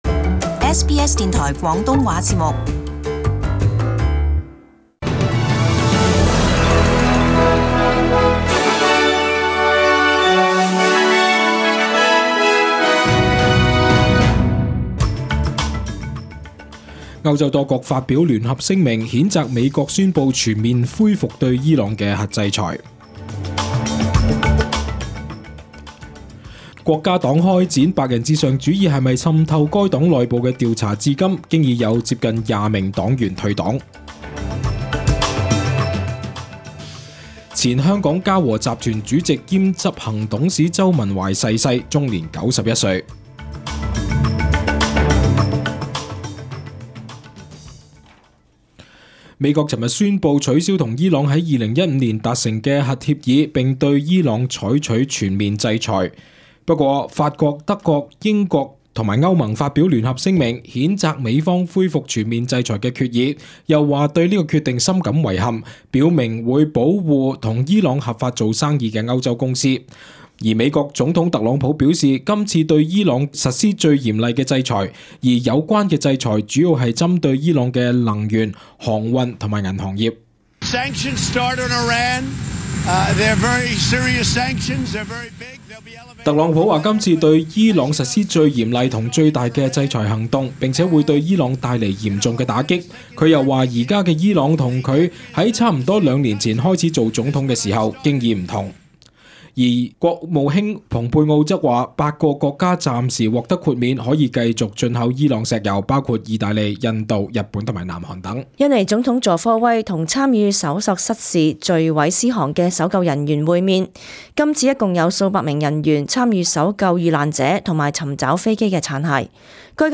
SBS中文新聞 （十一月三日）